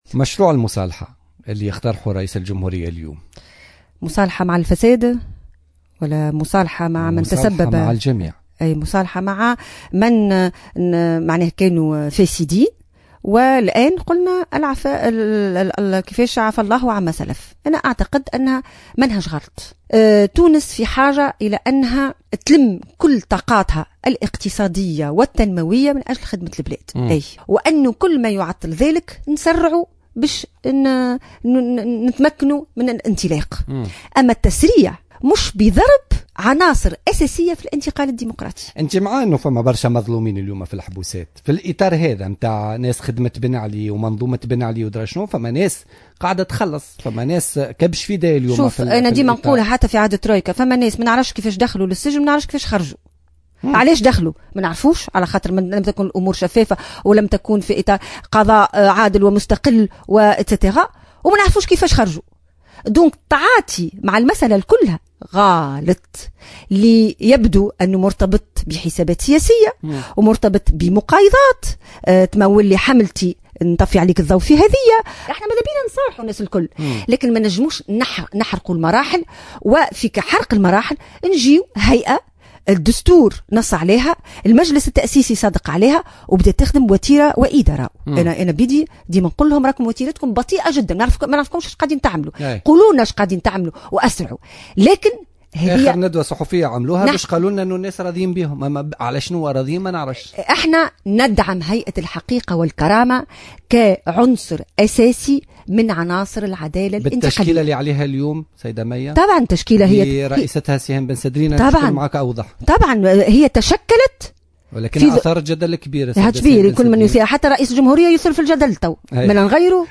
أكدت الأمينة العامة للحزب الجمهوري مية الجريبي ضيفة برنامج بوليتيكا اليوم الثلاثاء 4 أوت 2015 أنها مع المصالحة الوطنية على أن تتم على قواعد و محطات تدريجية باعتبار أن تونس في حاجة إلى ضم كل طاقاتها الاقتصادية والتنموية من أجل خدمة البلاد ولكنها ضد التسريع في ضرب عناصر أساسية في الانتقال الديمقراطي.